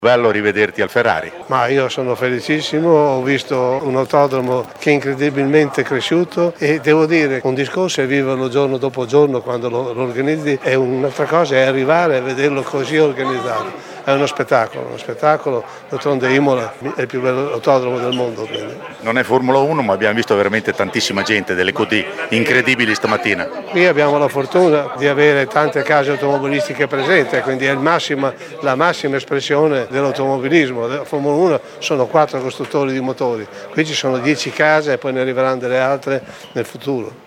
Gian Carlo Minardi, ex Presidente autodromo, consulente Motorsport e scopritore di talenti, vedi Antonelli